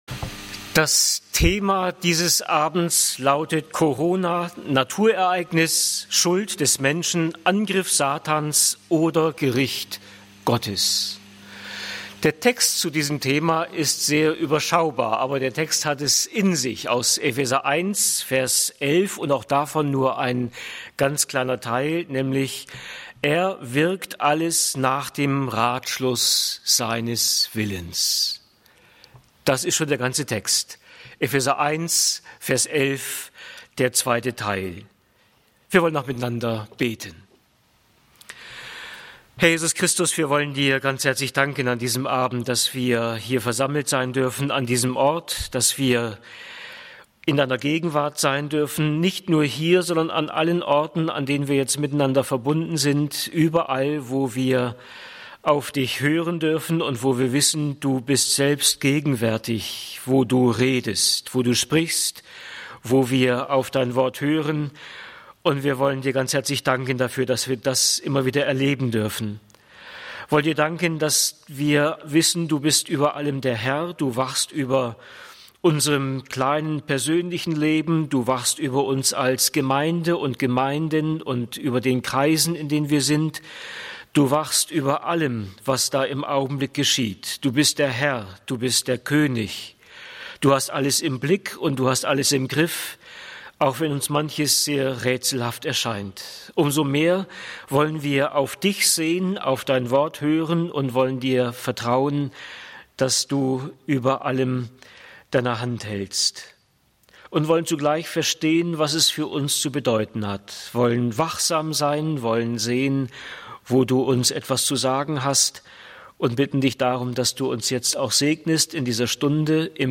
Corona – Naturereignis, Schuld des Menschen, Angriff Satans oder Gericht Gottes? -Bibelstunde